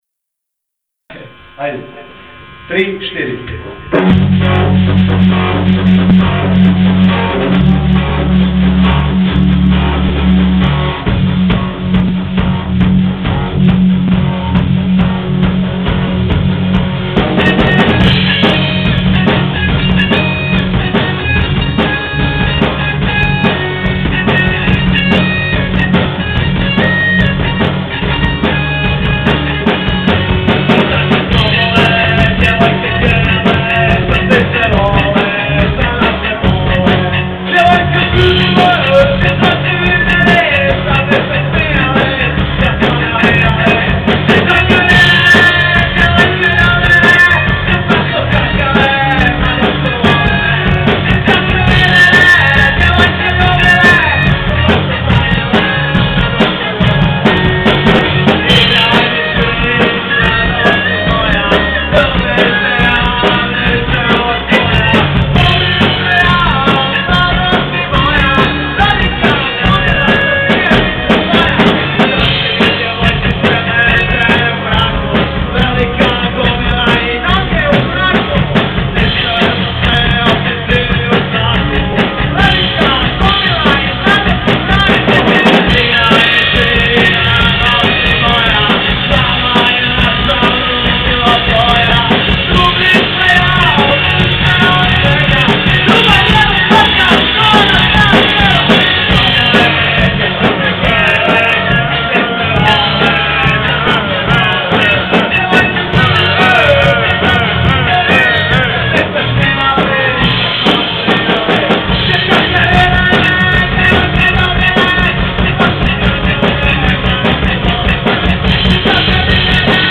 Već navečer himna je uglazbljena u izvedbi sućuranskih rokera "Ludilo Škarik" i "The Neće Nikako".